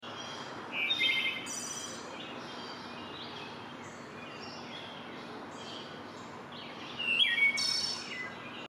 Wood Thrush birdsong is one sound effects free download
Wood Thrush birdsong is one of my favorite ways to reconnect with nature